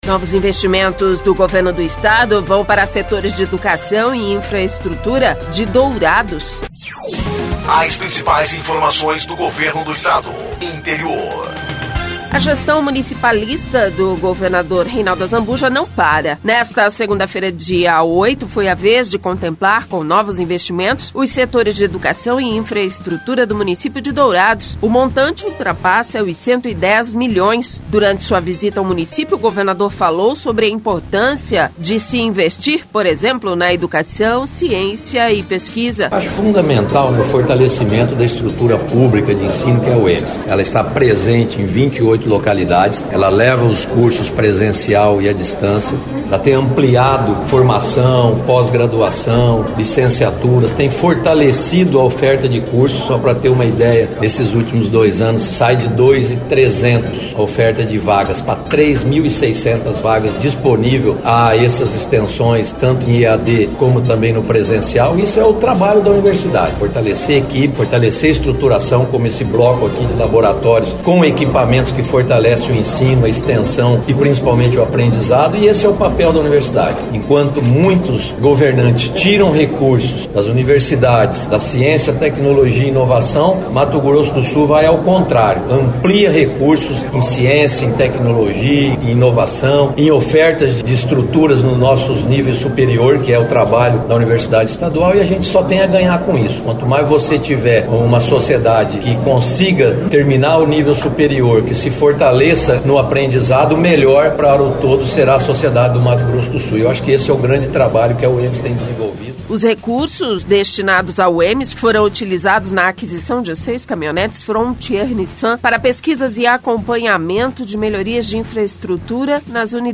Durante sua visita ao município, o governador falou sobre a importância de investir na educação, ciência e pesquisa
O secretário de infraestrutura Eduardo Riedel explicou que investir nos municípios é garantir um Estado saudável.